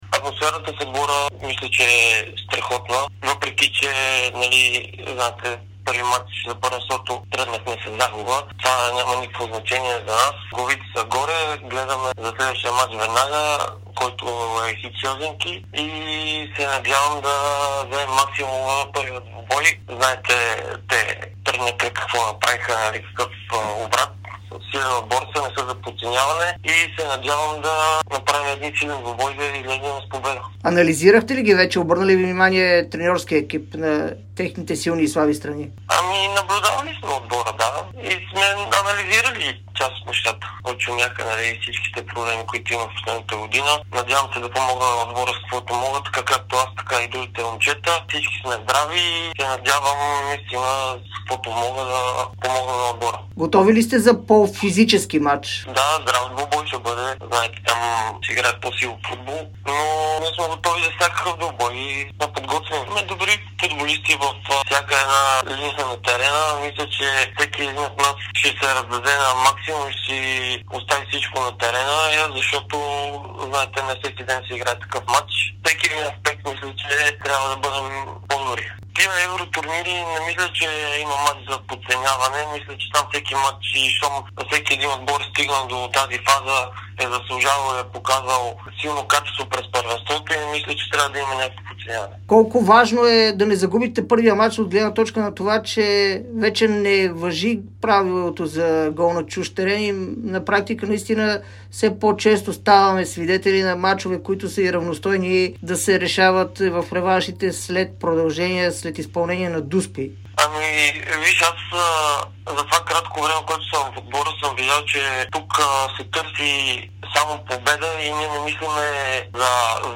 говори пред Дарик и dsport преди двубоя на тима от Кърджали срещу ХИК Хелзинки във втория предварителен кръг на Лигата на конференциите.